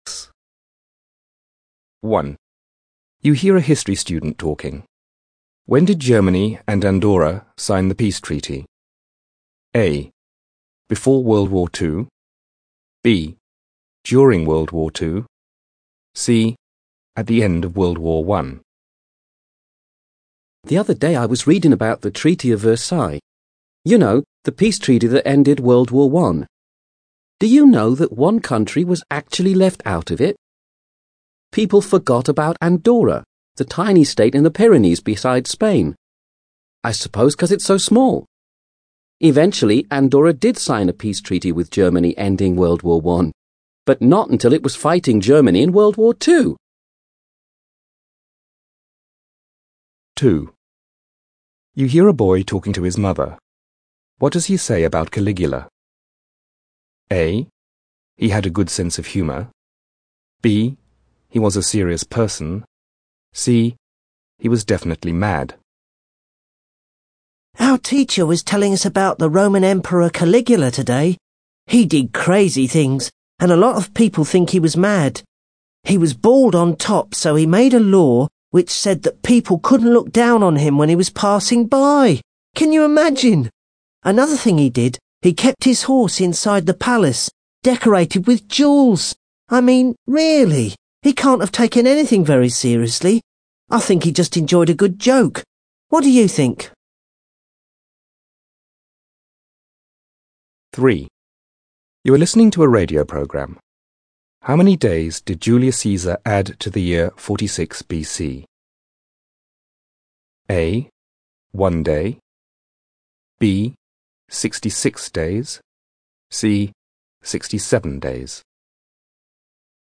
You will hear people talking in five different situations.